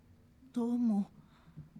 ボイス
ダウンロード 女性_「どうも」
大人女性挨拶